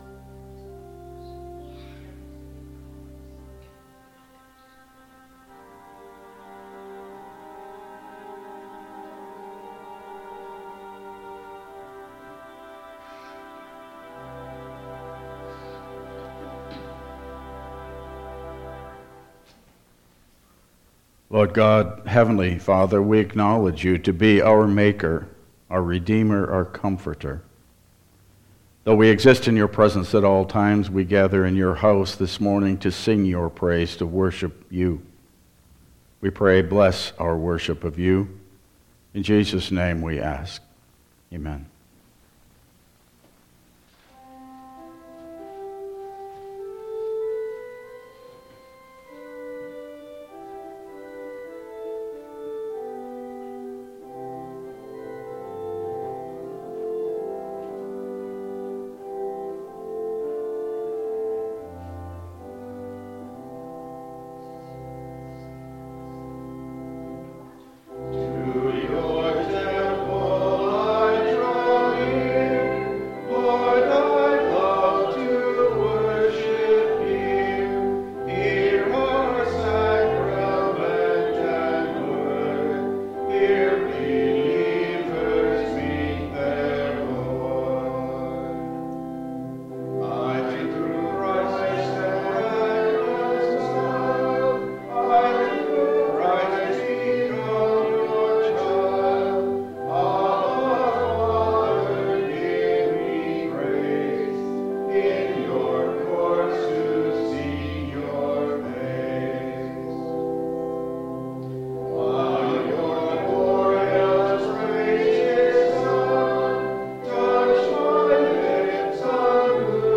Passage: Luke 9:51-56 Service Type: Regular Service